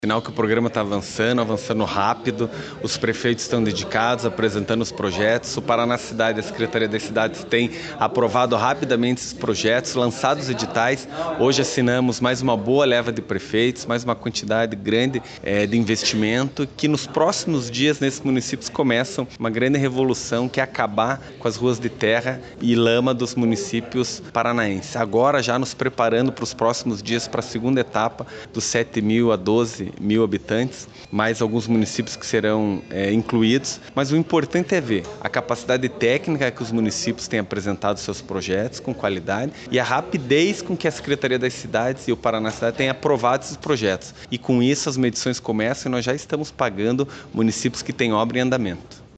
Sonora do secretário das Cidades, Eduardo Pimentel, sobre a liberação de mais R$ 53,8 milhões no Asfalto Novo, Vida Nova